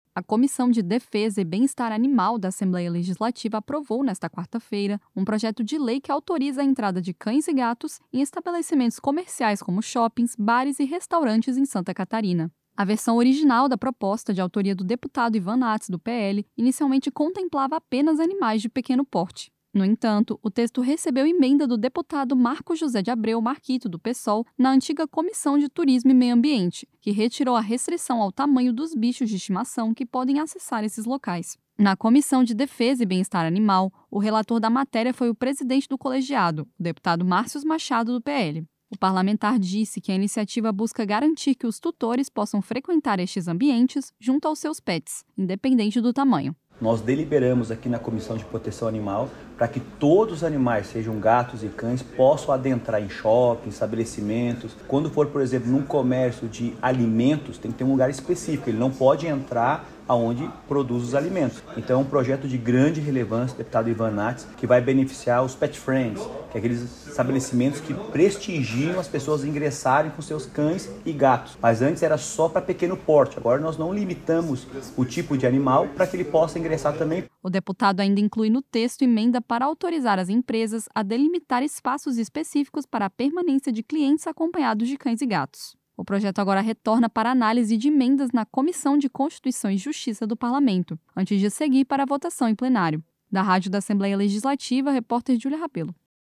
Entrevista com: